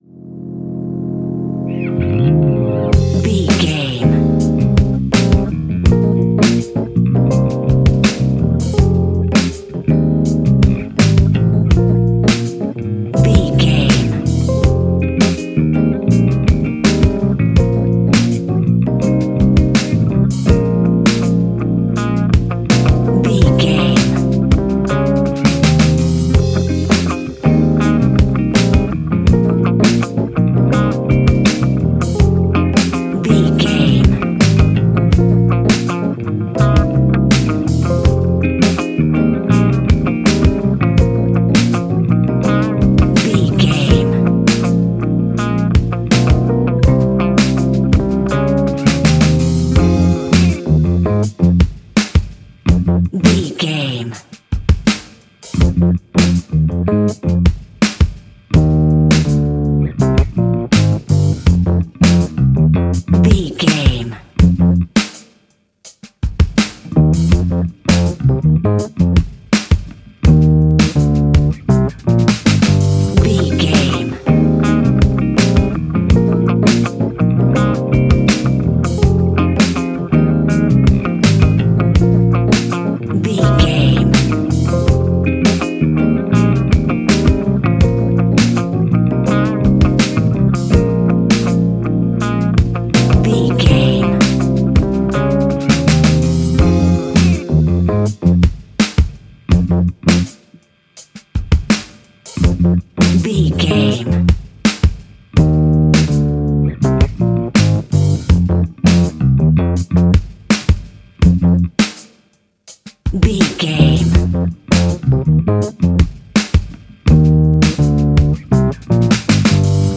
Epic / Action
Fast paced
In-crescendo
Uplifting
Ionian/Major
F♯
hip hop